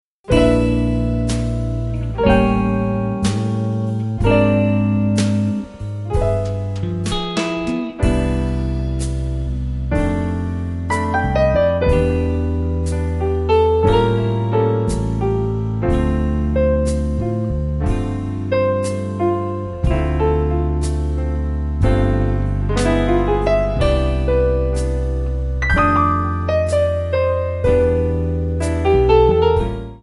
Backing track Karaoke
Pop, Oldies, Jazz/Big Band, 1960s